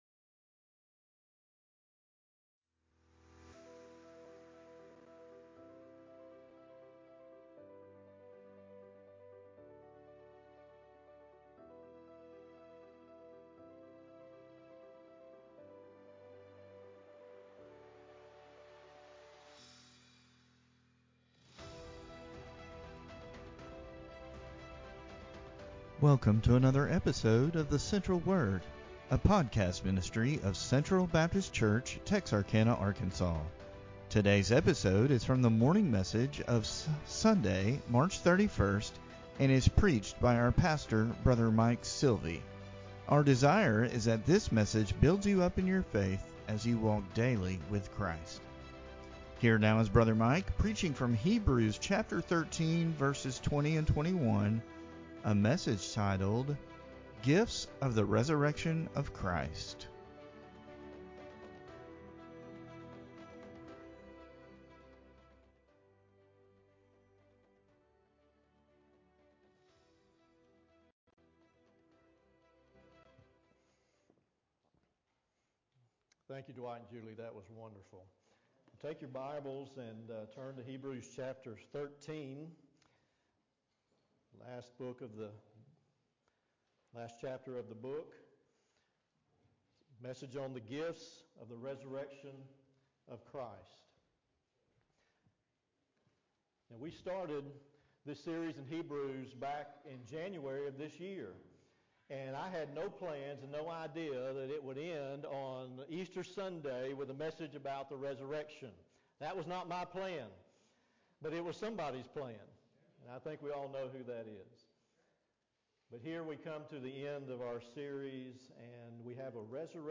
Mar31Sermon-CD.mp3